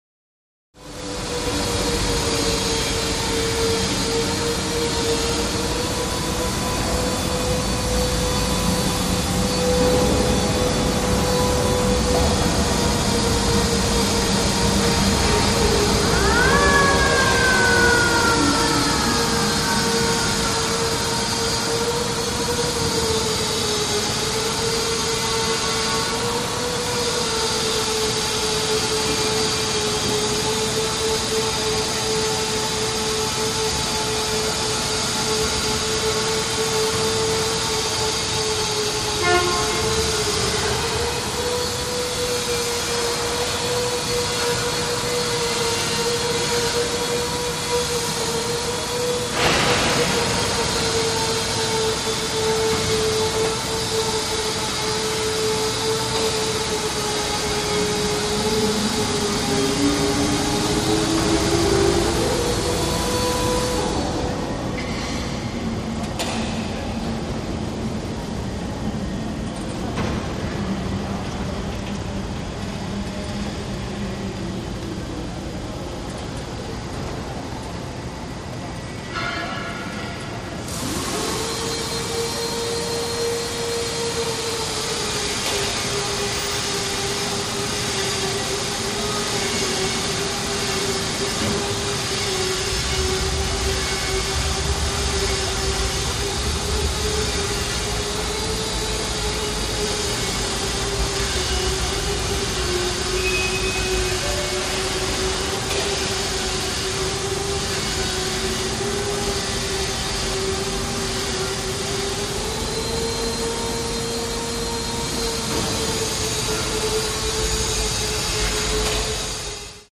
Dam Atmosphere; Interior Dam Atmosphere. General Echoed Activity With Electrical Sander Or Such, Sparse Voices And Occasional Bang. During Construction.